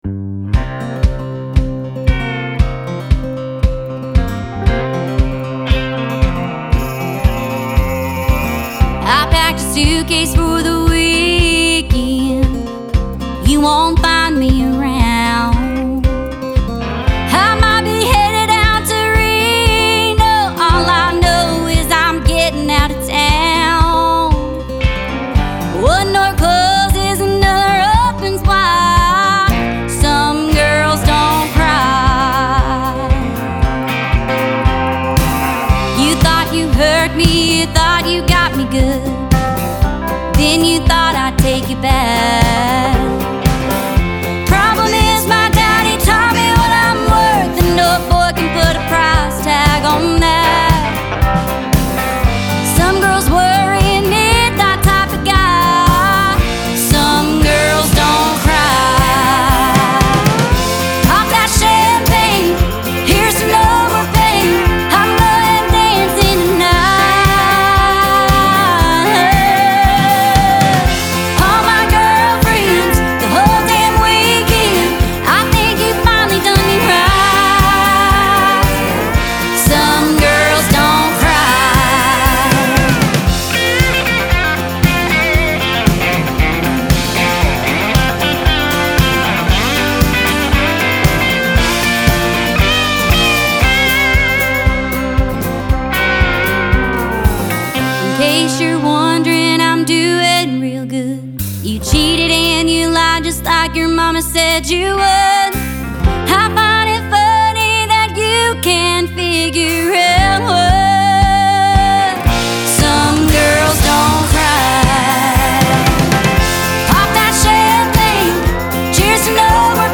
SONG GENRE – COUNTRY